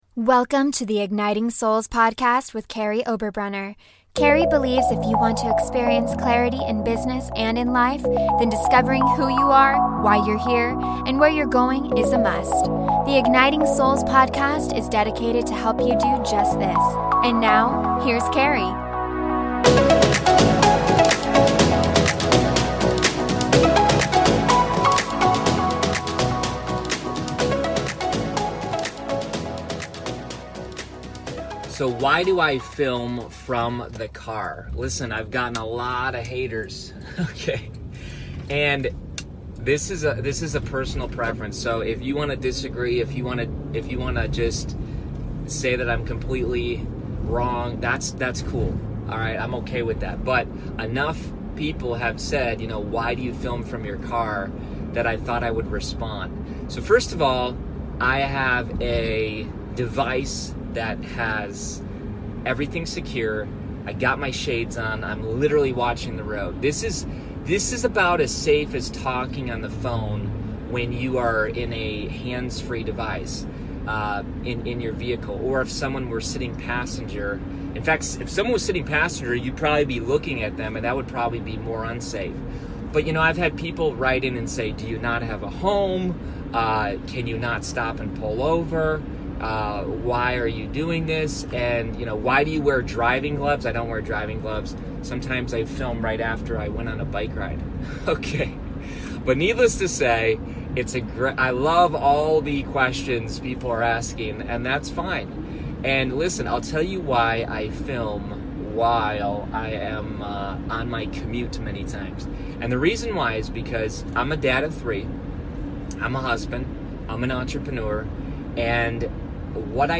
Why I record so many videos from my car
Why do I film my podcast from the car?